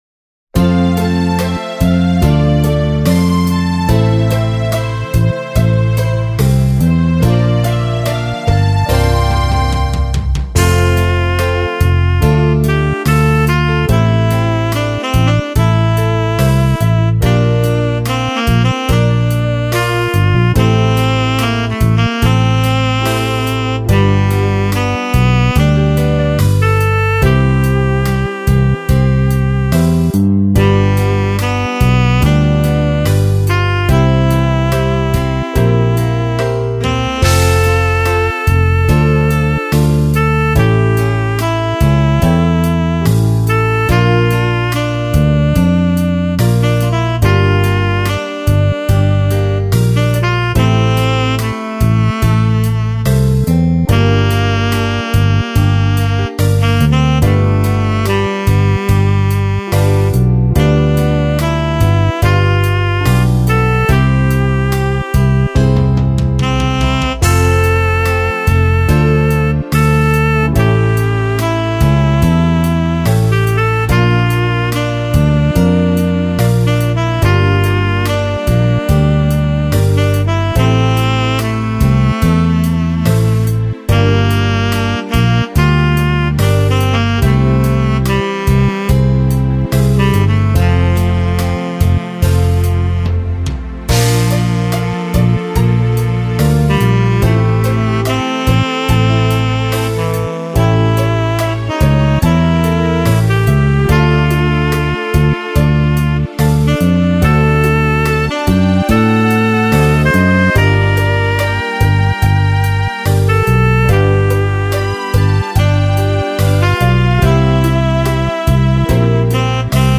2979   05:30:00   Faixa: 6    Clássica